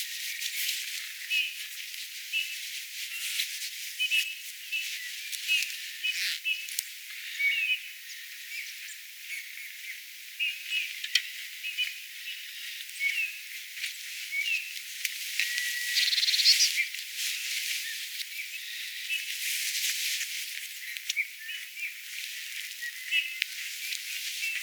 eka kertaa tulii-punatulkun ääntelyä.
ruokinnalla oli toinen punatulkku,
hieman_kuin_tuli-aantelyja_punatulkulla.mp3